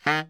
Index of /90_sSampleCDs/Giga Samples Collection/Sax/BARITONE DBL
BARI  FF D#2.wav